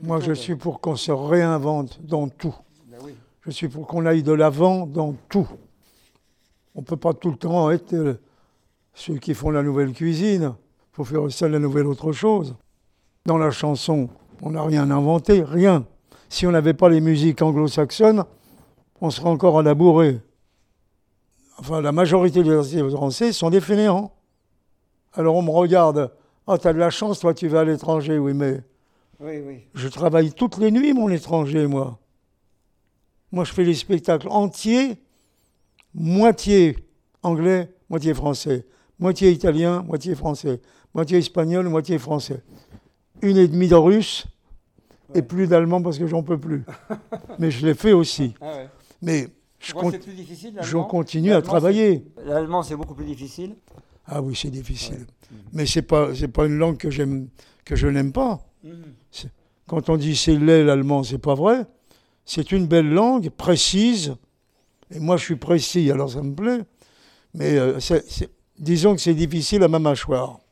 La confiance professionnelle qui s’est bâtie au fil des ans influe indéniablement sur la façon dont Charles Aznavour se livre ici. Entamés un an et demi avant sa mort, ces entretiens confèrent à cet ouvrage un caractère particulier.